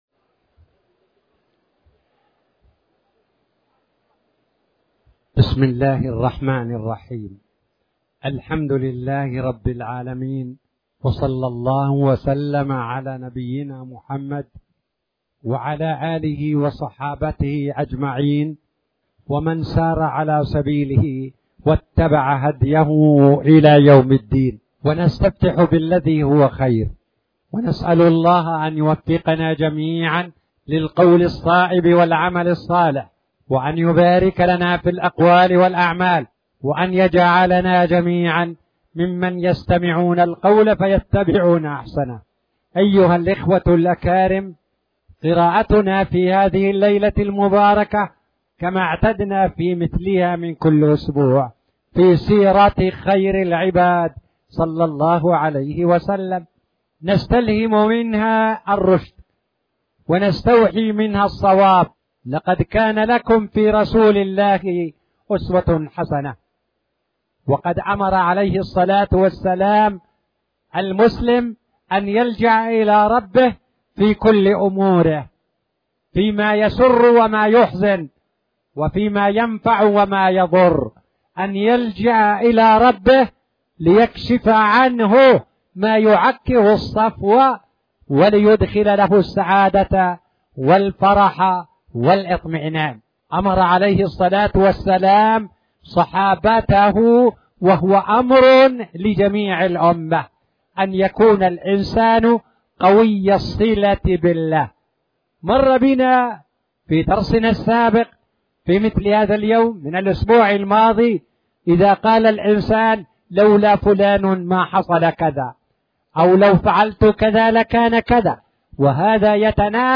تاريخ النشر ١٢ جمادى الأولى ١٤٣٨ هـ المكان: المسجد الحرام الشيخ